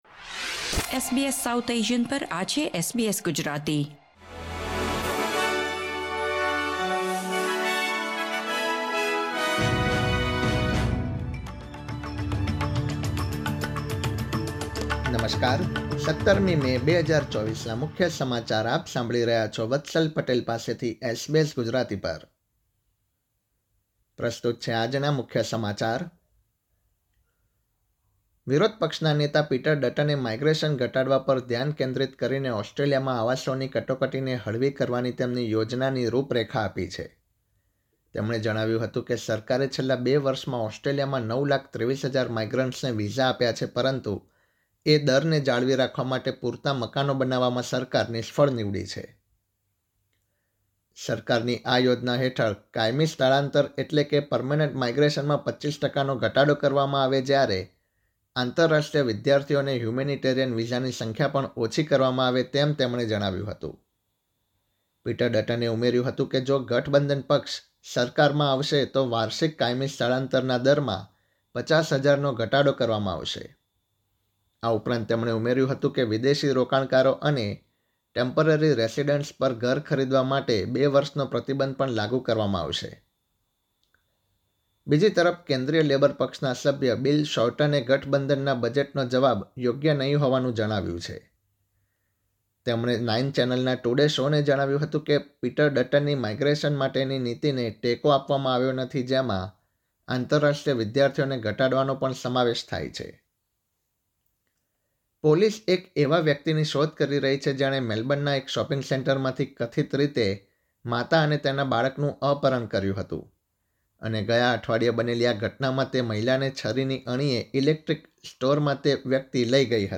SBS Gujarati News Bulletin 17 May 2024